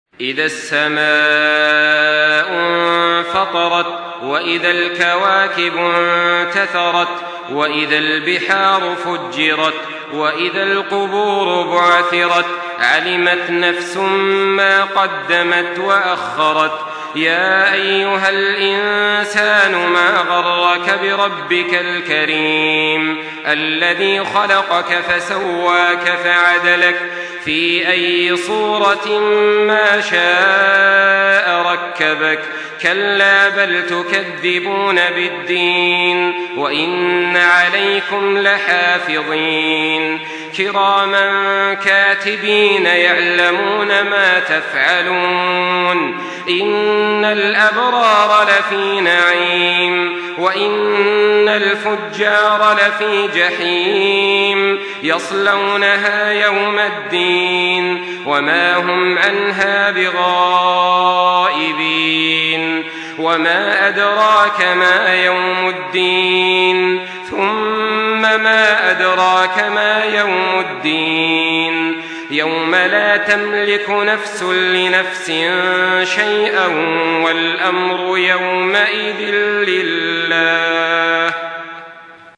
Surah Infitar MP3 by Makkah Taraweeh 1424 in Hafs An Asim narration.
Murattal Hafs An Asim